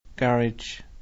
Irisch-Englisch